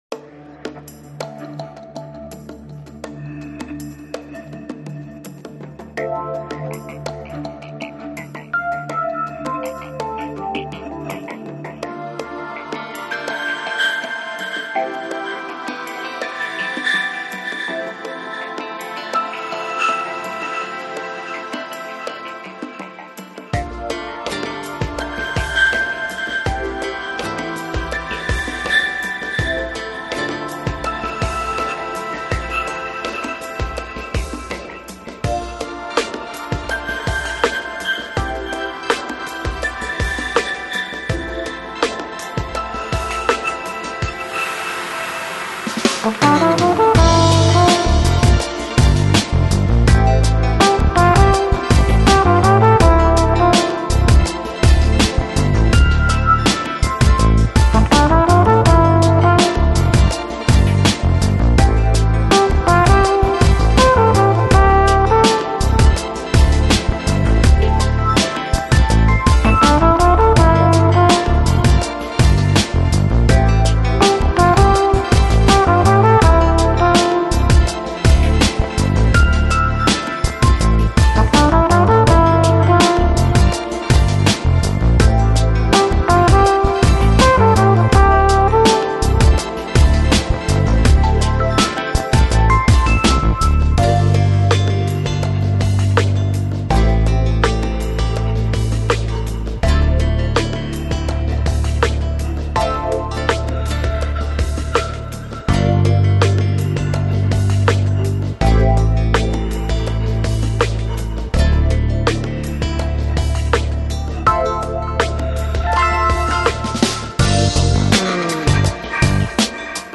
Chill Out, Lounge, Downtempo